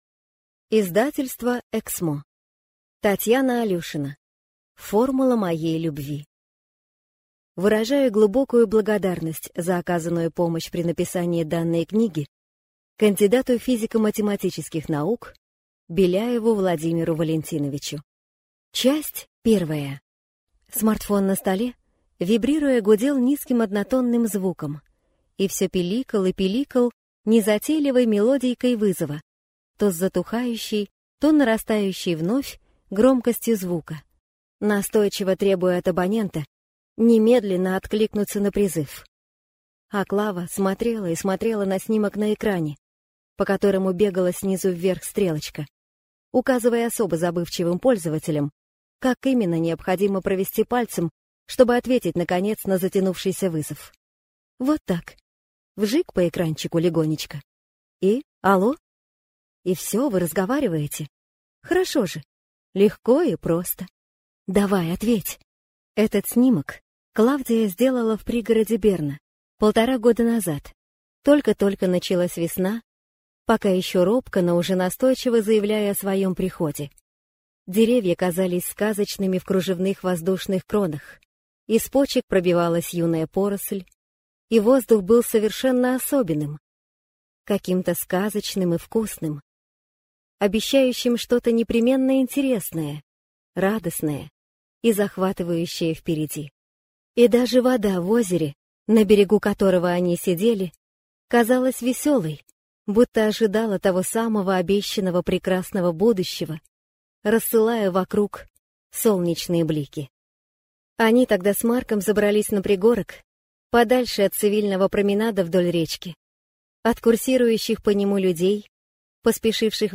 Аудиокнига Формула моей любви | Библиотека аудиокниг